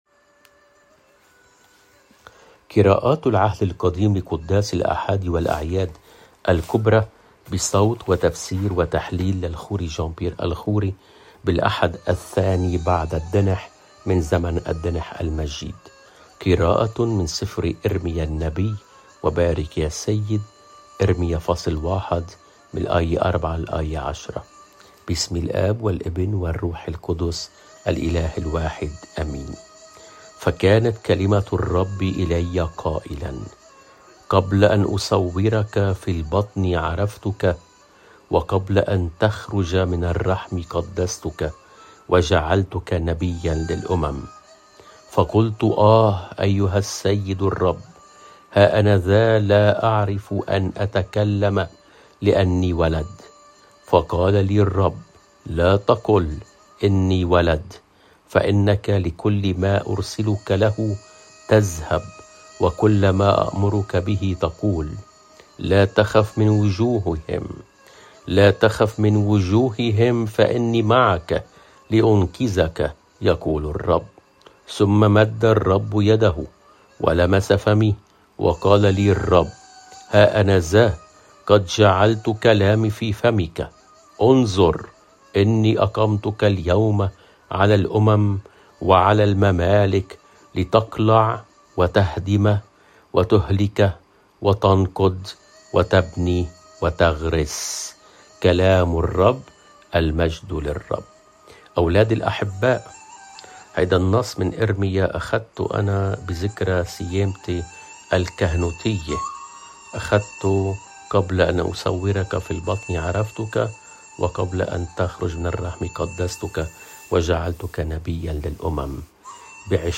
قراءة من العهد القديم :قِراءةٌ مِن سِفْرِ إرميّا النبيّ (إرميا ١ / ٤ - ١٠ )